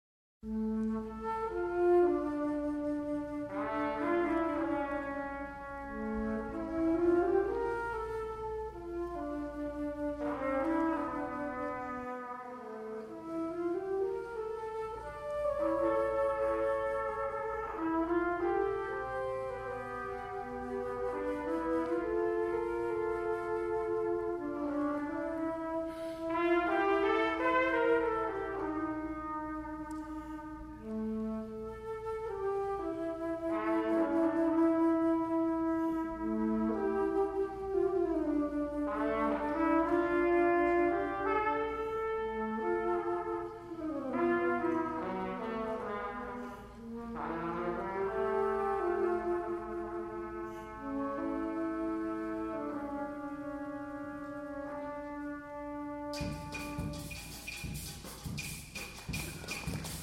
Saxophone